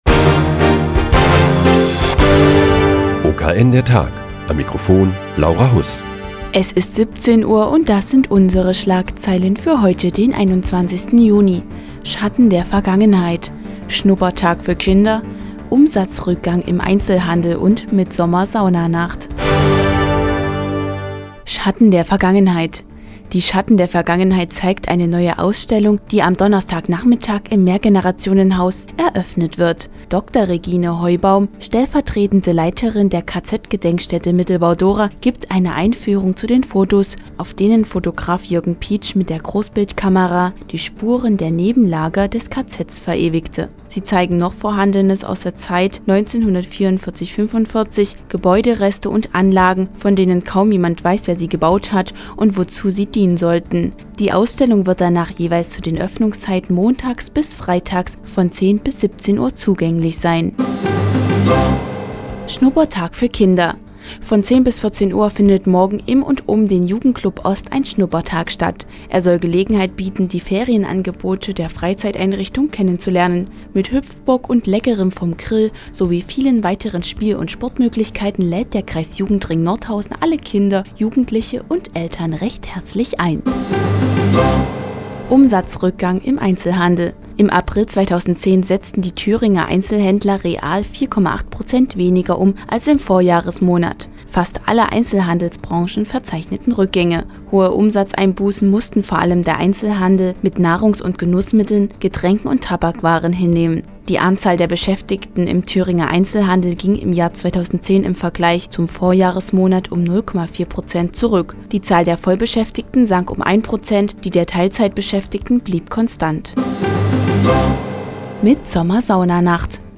Die tägliche Nachrichtensendung des OKN ist nun auch in der nnz zu hören. Heute geht es um die neue Ausstellung im Mehrgenerationenhaus und die "Mittsommer- Saunanacht" im Badehaus Nordhausen.